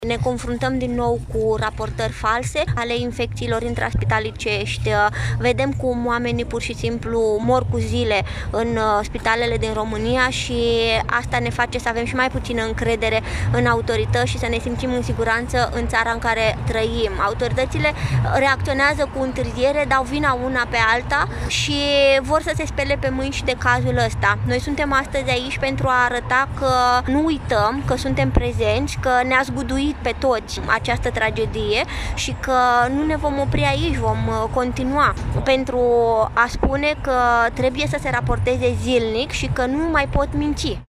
Peste o sută de cetățeni au protestat tăcut, astăzi, în Piața Națiunii din Iași , în fața Universității de Medicină și Farmacie „Grigore T. Popa”.